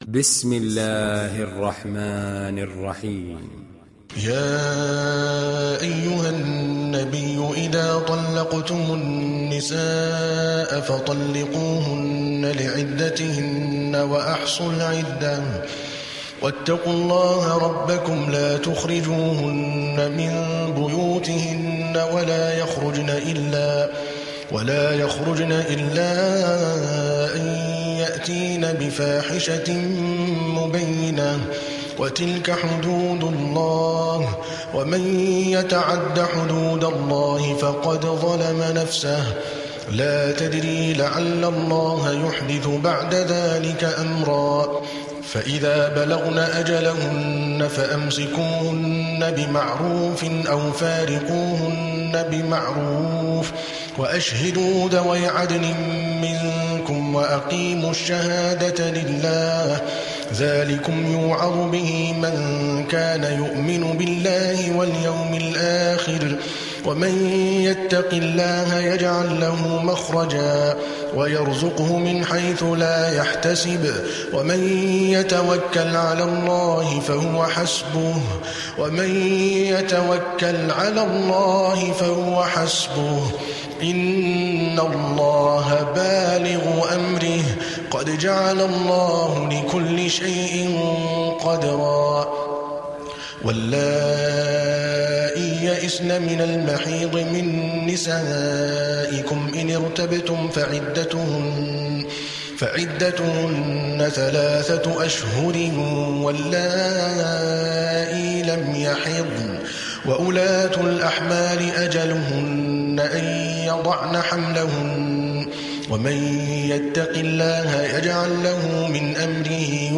Talak Suresi İndir mp3 Adel Al Kalbani Riwayat Hafs an Asim, Kurani indirin ve mp3 tam doğrudan bağlantılar dinle